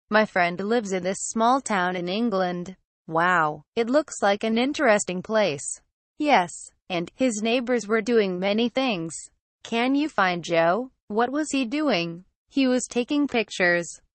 Conversation Dialog #2: